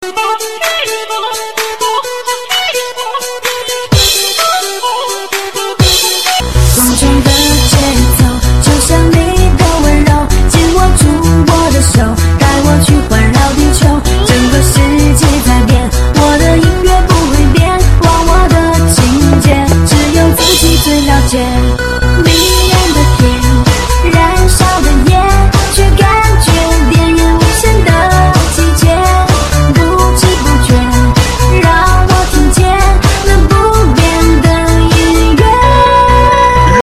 分类: DJ铃声
DJ舞曲